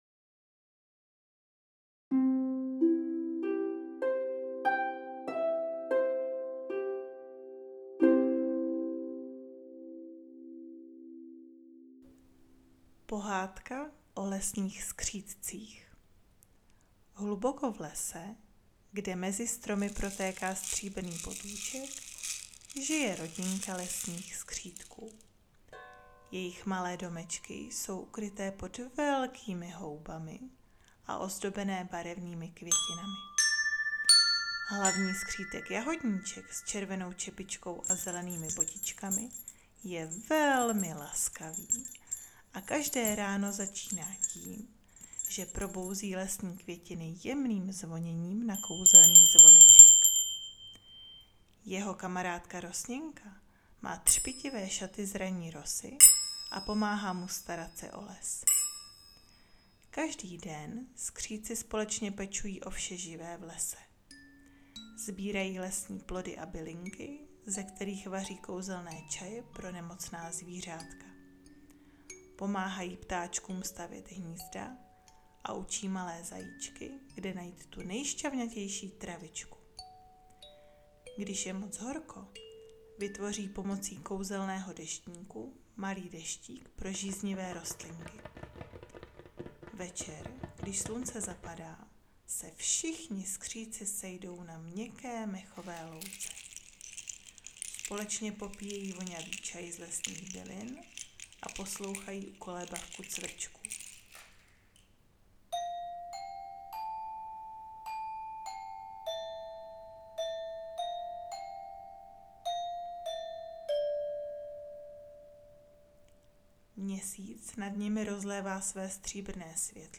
Použité hudební nástroje: Křišťálové mísy, Tibetské mísy, Rotující vlny, Barevné zvonky, Metalofon, Ocean Drum, Tongue Drum, Zvonkohry, Djembe, Citera, Orffovy nástroje
Pohadka-o-lesnich-skritcich-.mp3